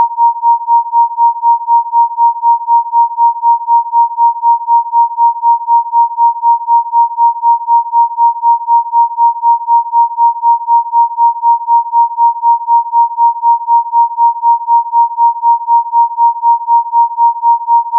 4 D Consciousness Activation Tone Mp 3